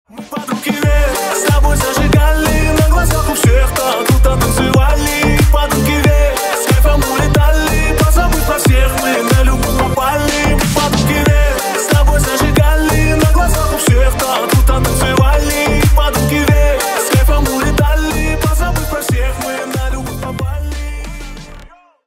на русском клубные про любовь